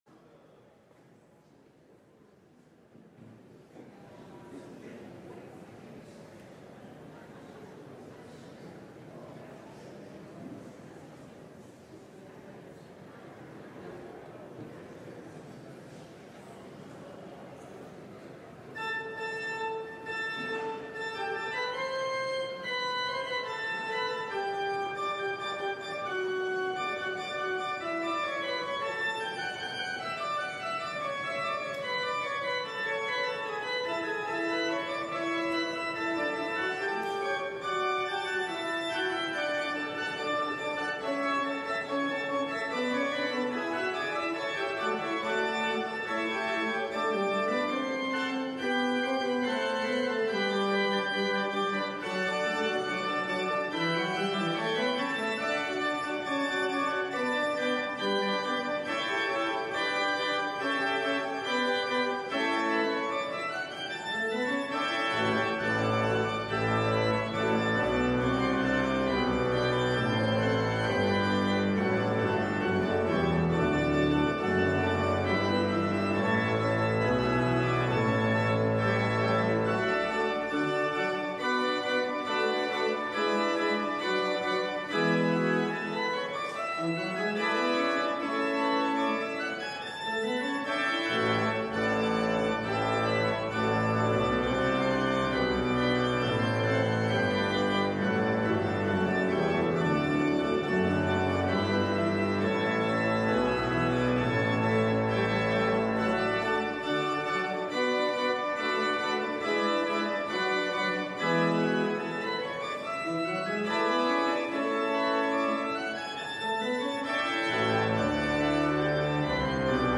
LIVE Morning Worship Service - Faith 101: The People of God